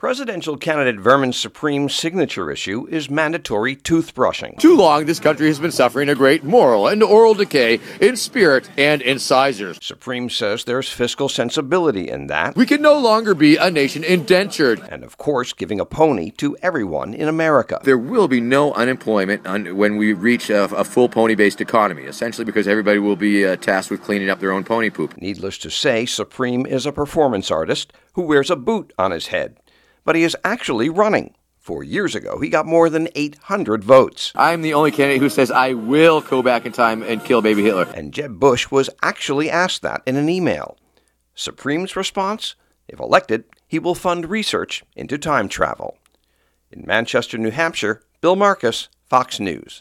Vermin Supreme campaigning for president on Friday night, Feb 5, on Elk Street in Manchester, NH